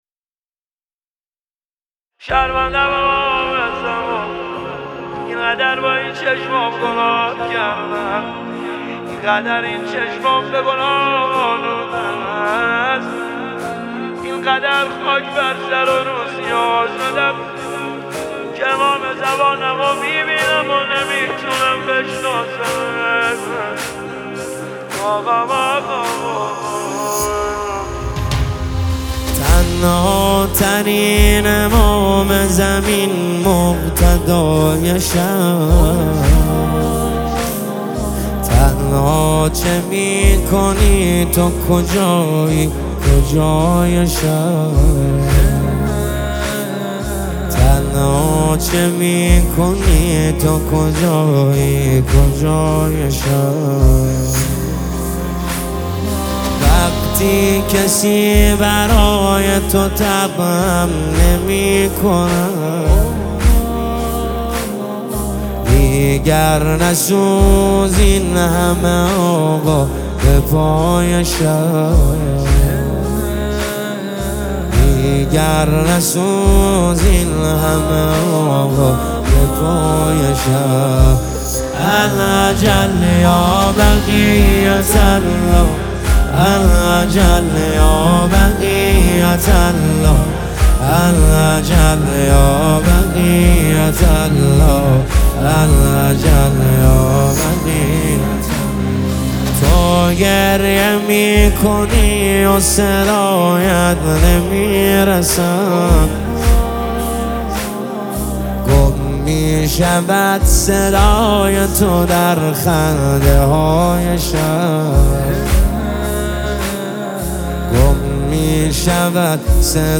برچسب ها: جمعه های انتظار ، نماهنگ مذهبی ، امام زمان (عج)